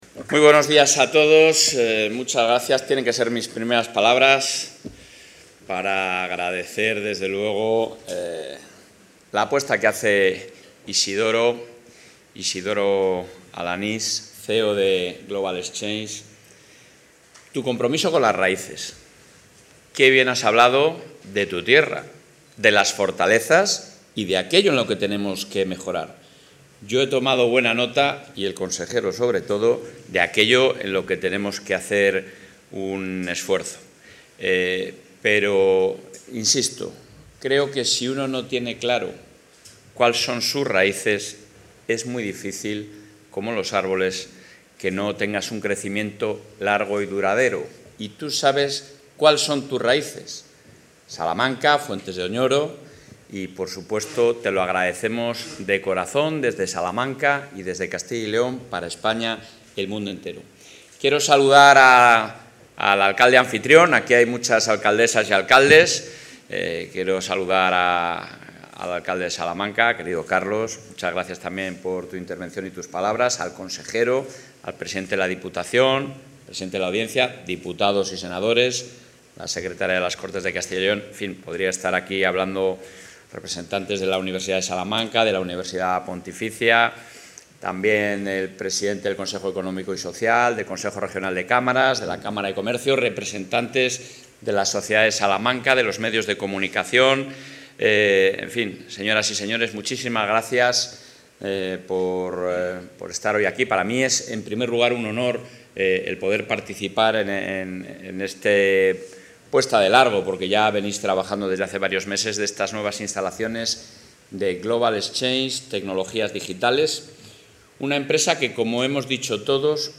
Intervención del presidente.
El presidente de la Junta de Castilla y León, Alfonso Fernández Mañueco, ha inaugurado hoy las nuevas instalaciones de la multinacional `Global Exchenge Tecnologías Digitales´ en Salamanca, que son, como ha indicado, un ejemplo del atractivo de la Comunidad y del compromiso del Gobierno autonómico con las empresas que deciden invertir y crear empleo.